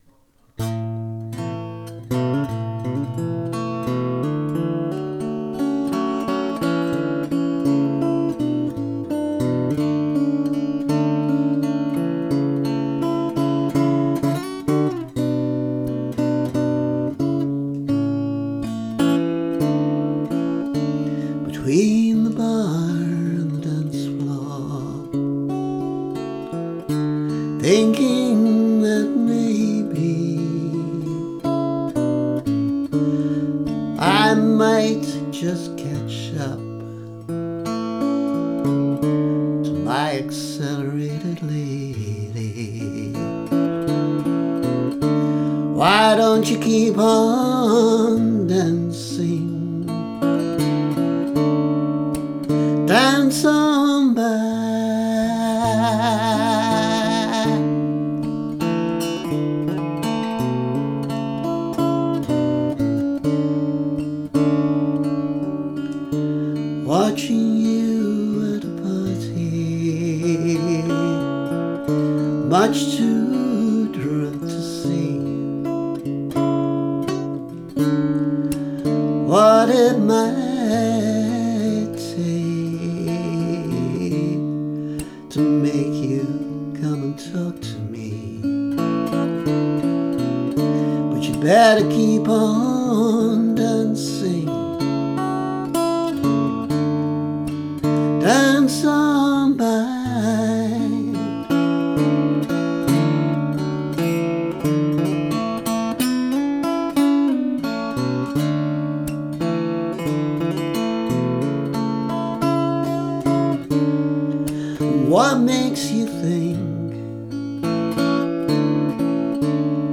Accelerated Lady [demo]